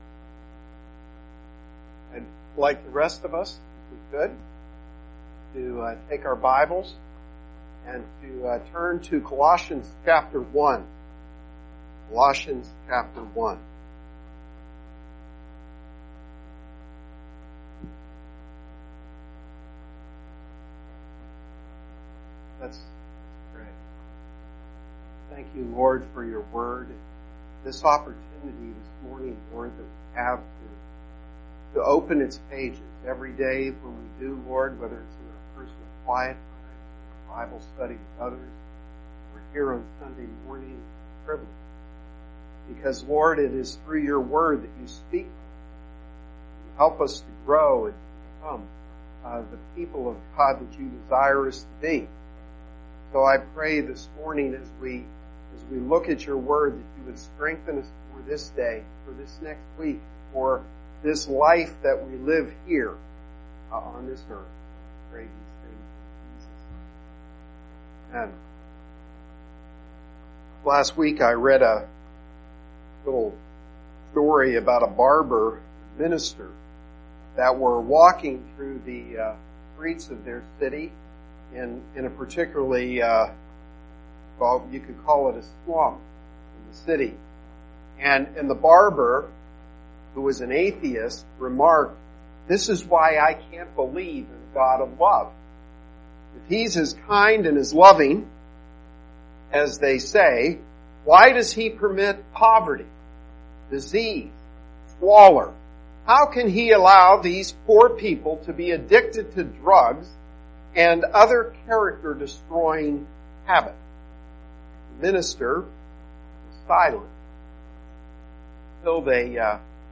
Sermon-8-19-18.mp3